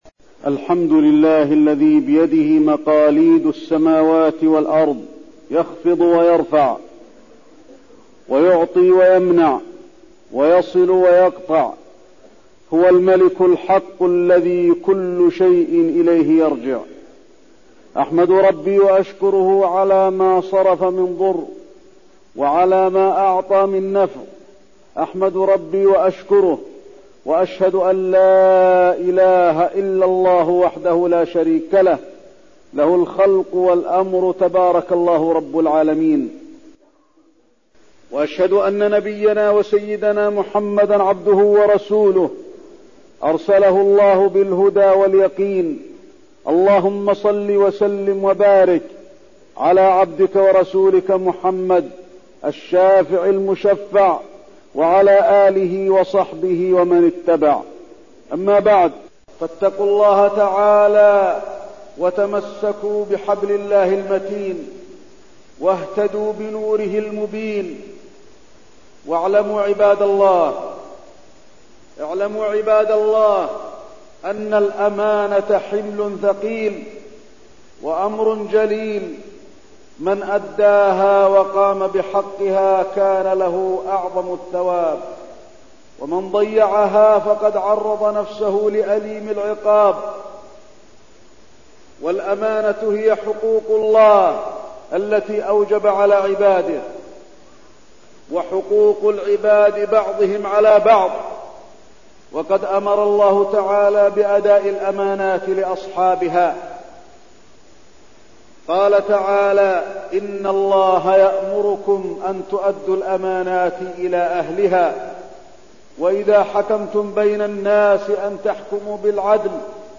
تاريخ النشر ١٣ ربيع الثاني ١٤١٣ هـ المكان: المسجد النبوي الشيخ: فضيلة الشيخ د. علي بن عبدالرحمن الحذيفي فضيلة الشيخ د. علي بن عبدالرحمن الحذيفي الأمانة في الوظائف